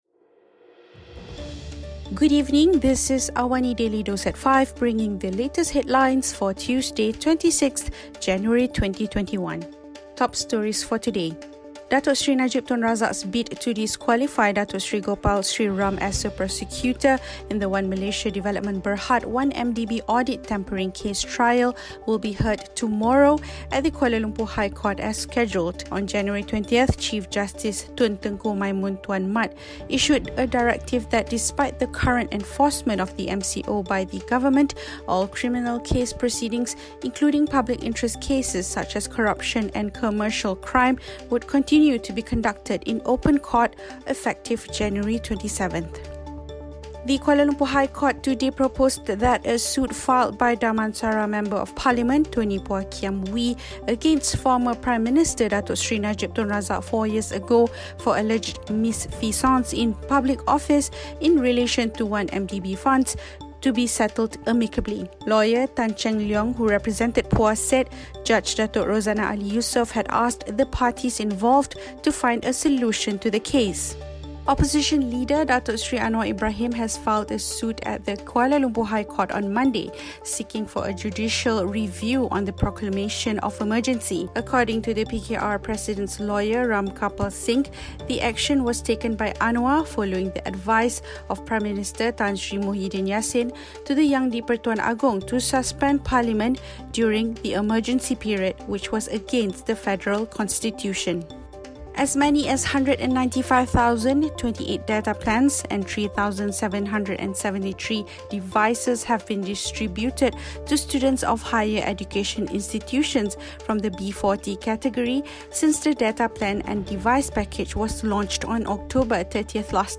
Listen to the top stories of the day, reporting from Astro AWANI newsroom — all in 3 minutes.